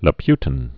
(lə-pytn)